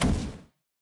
|技能音效
|爆炸箭矢发射和命中音效
ArcherQueen_super_hit.wav